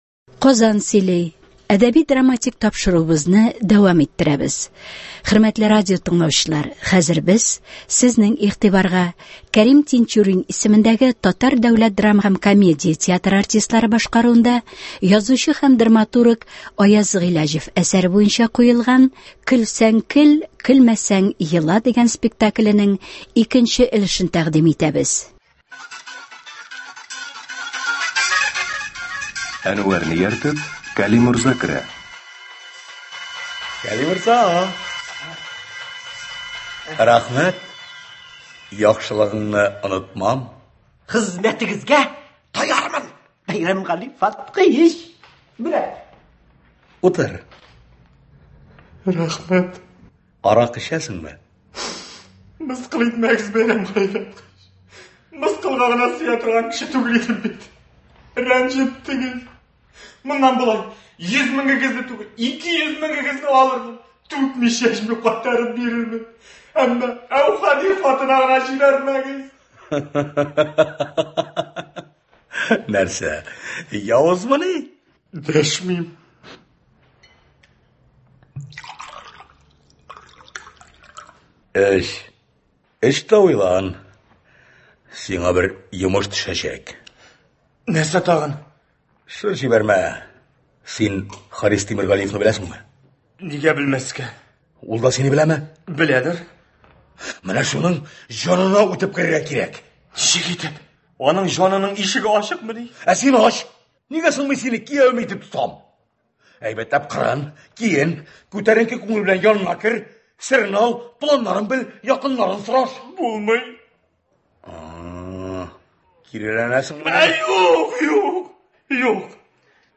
ТДДКТ спектакленең радиоварианты.